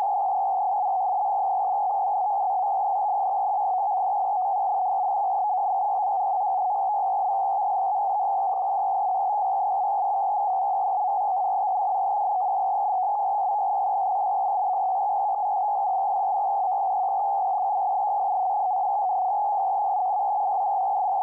сигнал_46_khz_CW
46_khz_cw.wav